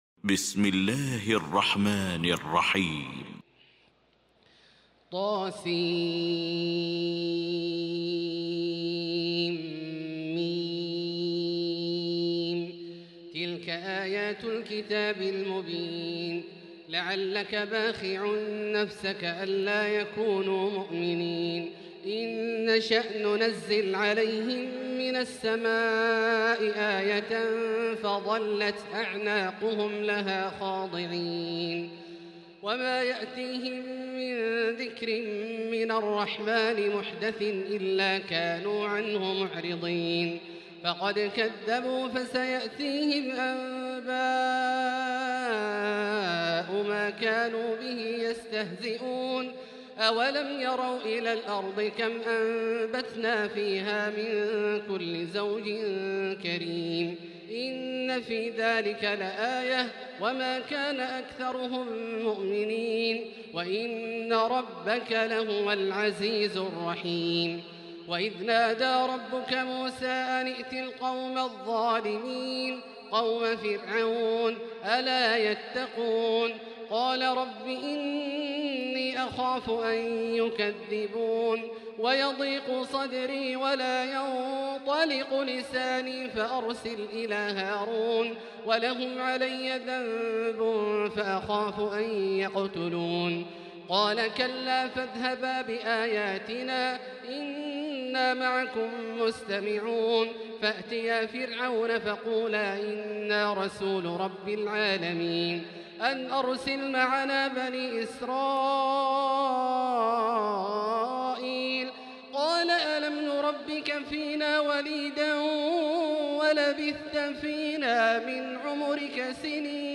المكان: المسجد الحرام الشيخ: فضيلة الشيخ عبدالله الجهني فضيلة الشيخ عبدالله الجهني فضيلة الشيخ ياسر الدوسري الشعراء